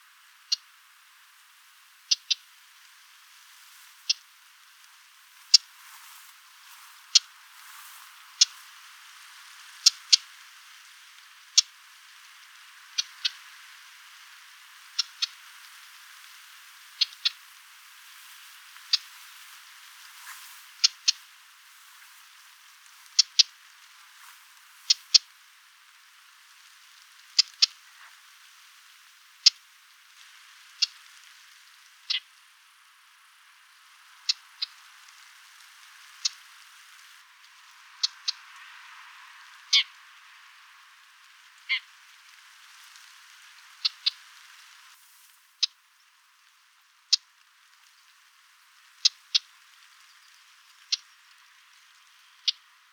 Lanius collurio - Red-backed shrike - Averla piccola
E 28°28' - ALTITUDE: 100 m. - VOCALIZATION TYPE: three different call types.
Note that the third call type (see spectrograms) is a clearer mimicry of sparrow call compared to Recording 3. Background: wind noise.